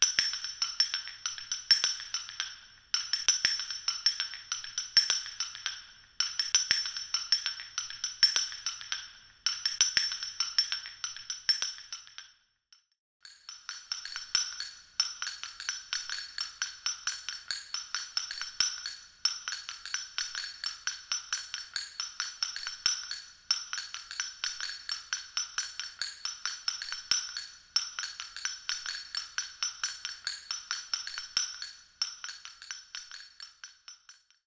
Instalación: Talla en piedra de río y sonido. Dimensiones variables
Instrumento musical idiófono golpeado de forma no directa. Está compuesto por dos partes que componen el ritmo al golpearse. Su diseño está basado en las tarrañuelas vascas semejantes a unas castañuelas.